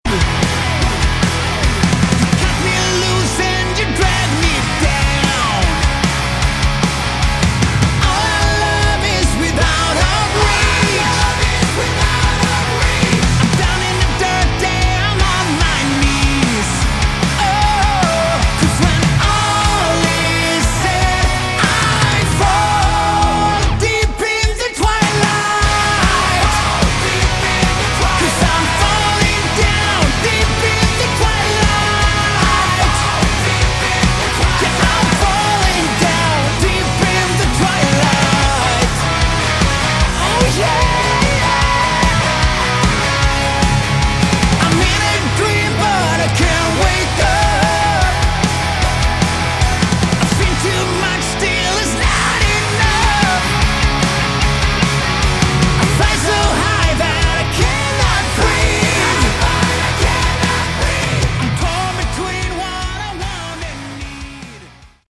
Category: Hard Rock
Vocals
Guitars
Drums
Bass